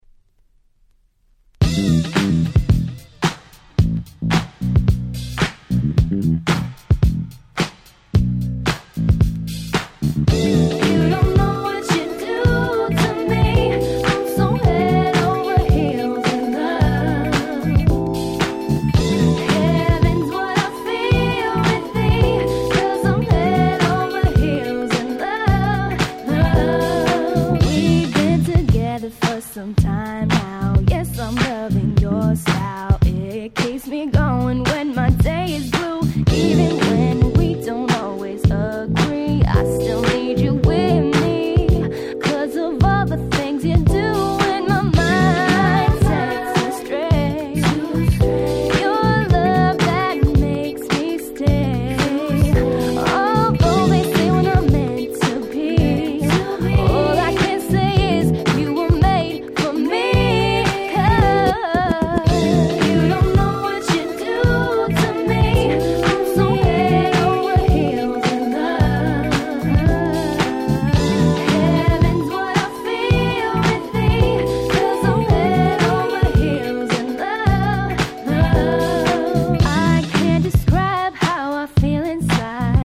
06' Nice Miner R&B !!
詳細は不明ですが、2006年にマイナーレーベルからひっそりとリリースされた良質女性Vocal R&B !!
程良いキャッチーさでなかなかナイスです！